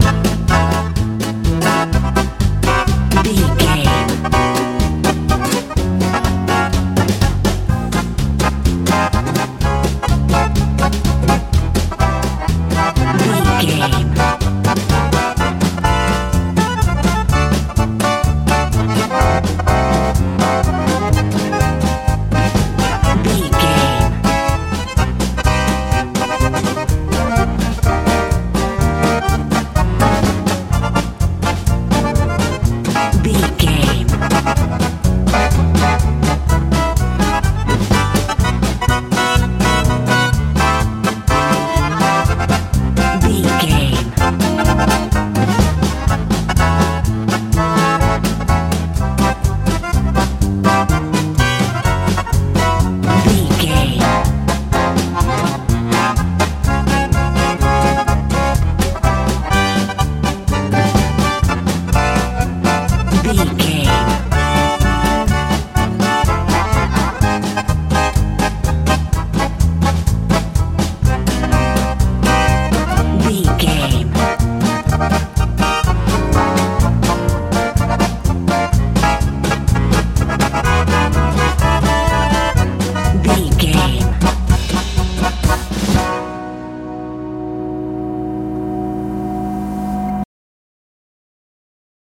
polka
Ionian/Major
groovy
playful
accordion
bass guitar
drums
driving
cheerful/happy
lively
joyful